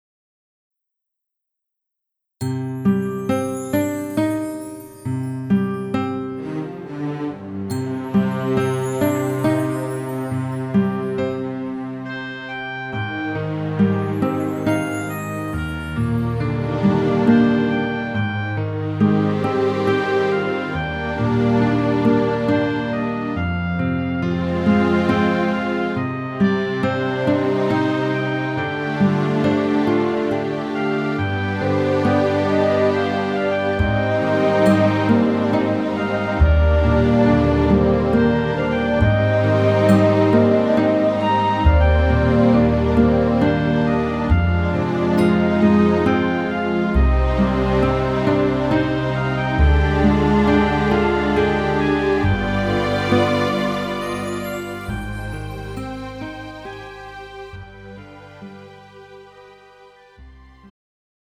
음정 -1키 4:04
장르 가요 구분 Pro MR